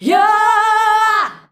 YOOOAAH A.wav